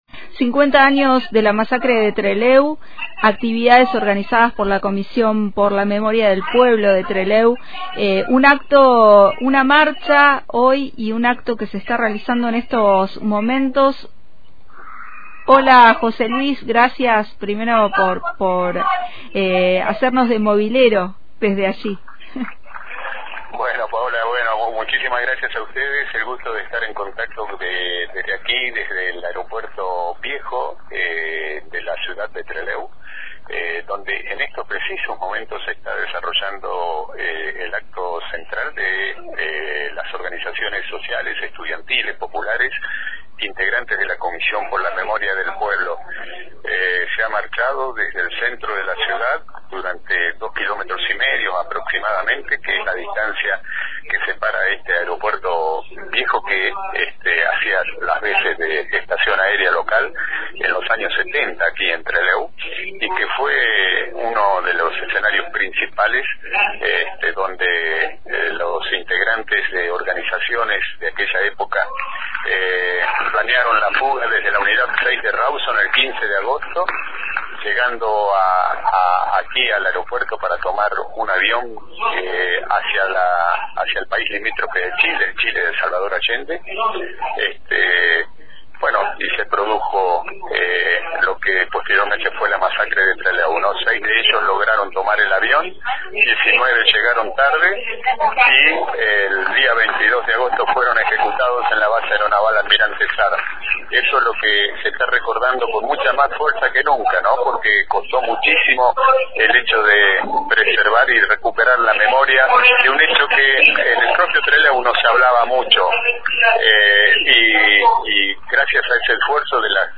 Desde el «Aeropuerto Viejo», las organizaciones sociales, políticas, sindicales e independientes de los gobiernos participan del acto organizado por la Comisión por la Memoria del Pueblo de Trelew después de haber marchado desde el centro de la ciudad hasta el lugar.